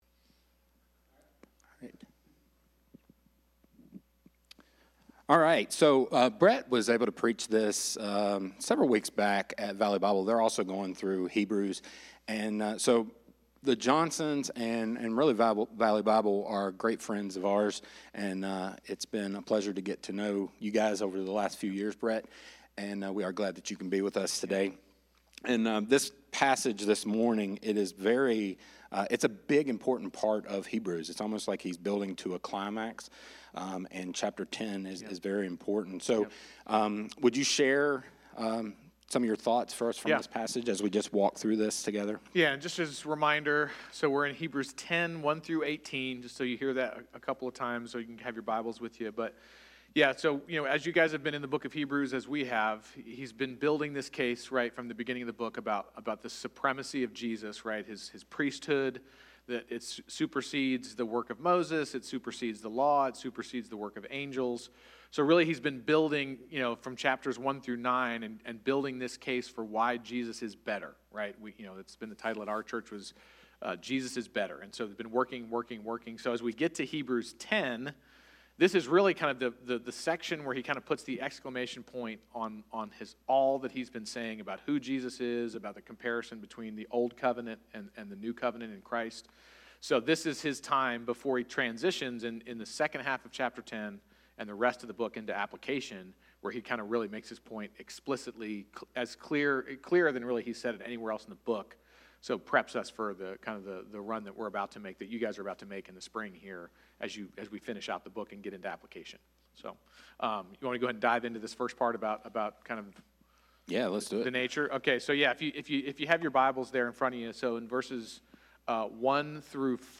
sermon-audio.mp3